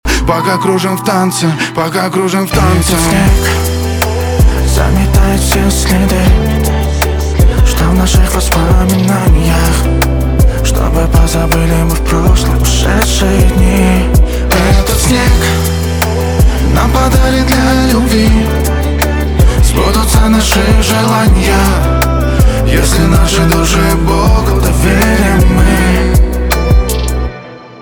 поп
романтические
грустные , битовые